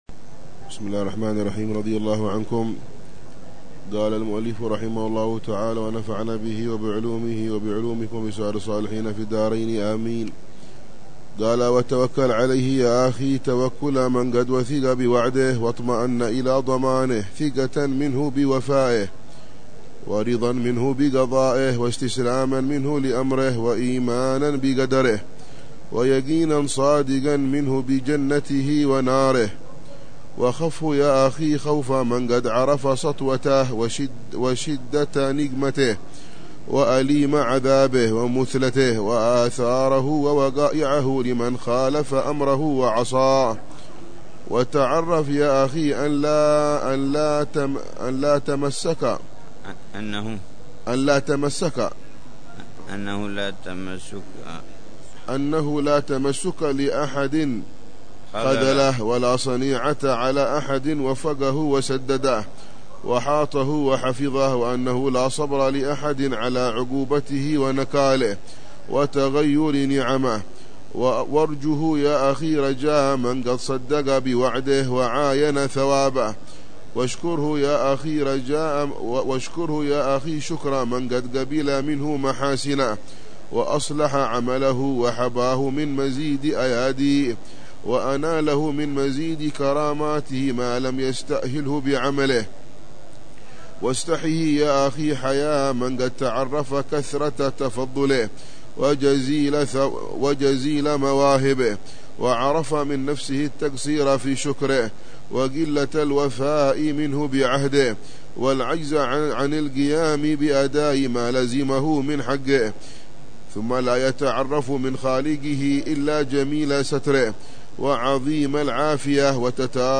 الدرس الثاني من دروس الحبيب عمر بن حفيظ في شرح كتاب آداب النفوس للإمام أبي عبد الله الحارث المحاسبي، يتحدث عن أهمية تهذيب النفس وتزكيتها والن